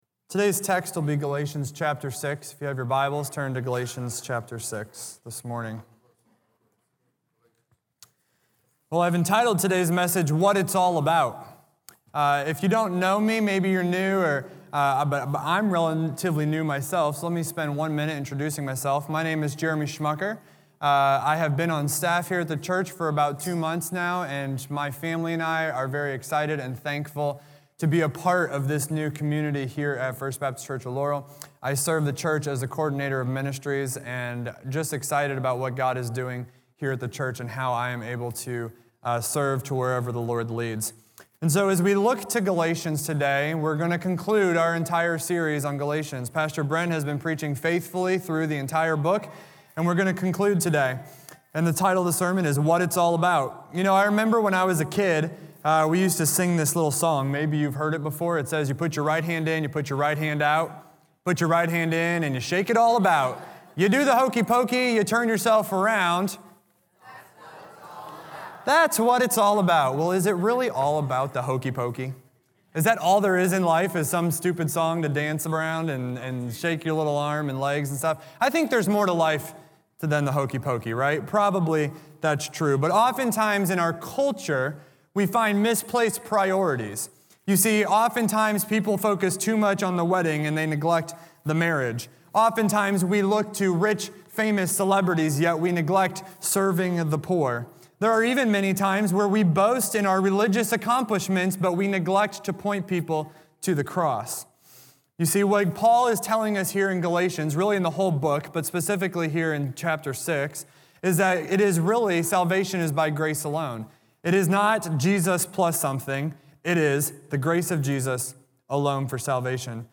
A message from the series "Ekklesia Rhythms."